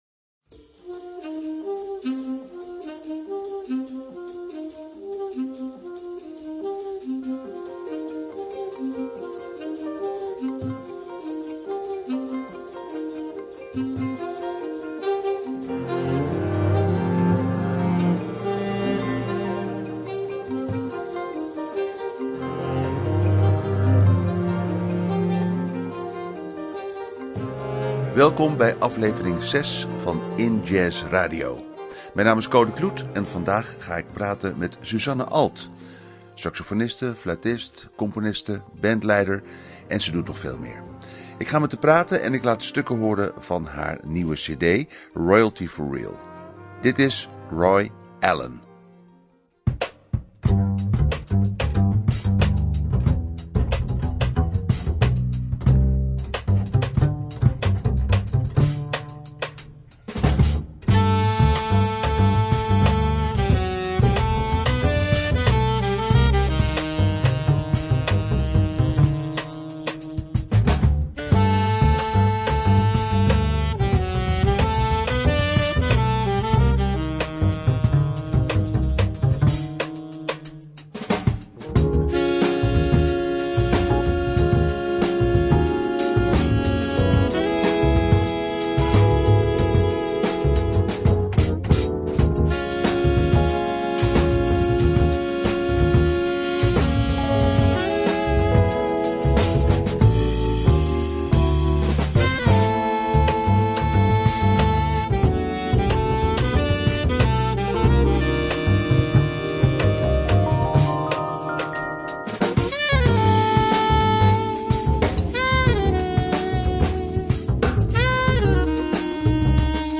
In gesprek met